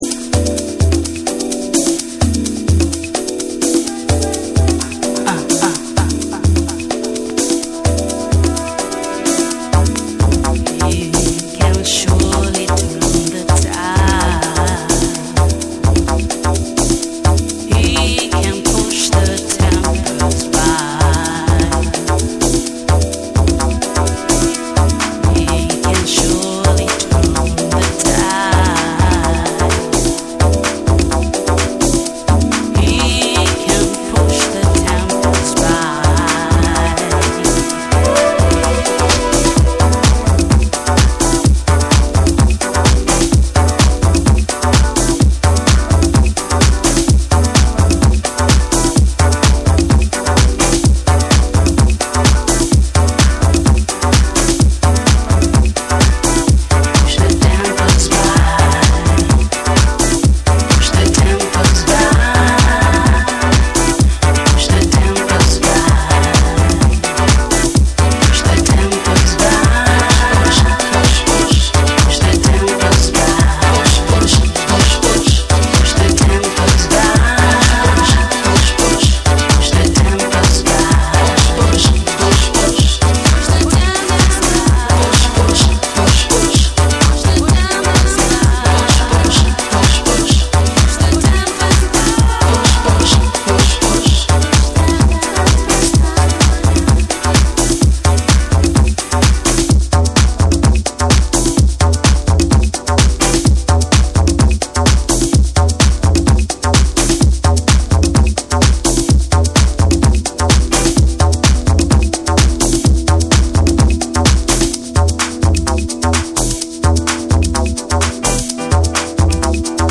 BREAKBEATS / TECHNO & HOUSE